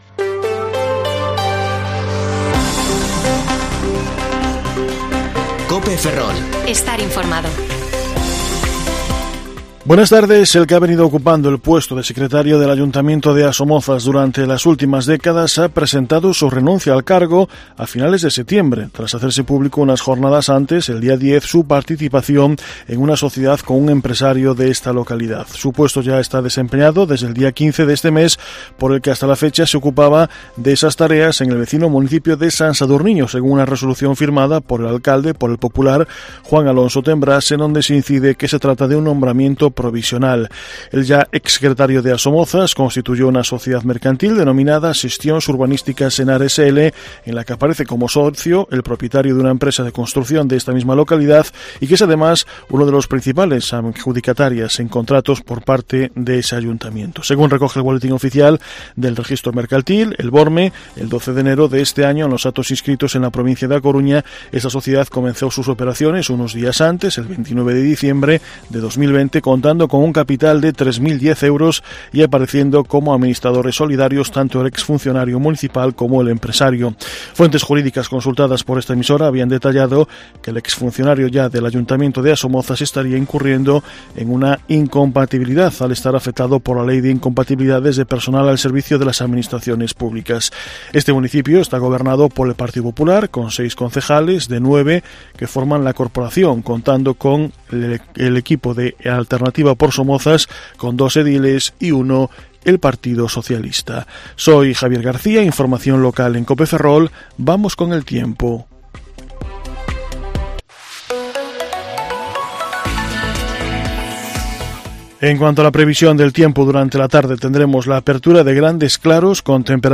Informativo Mediodía COPE Ferrol 25/10/2021 (De 14,20 a 14,30 horas)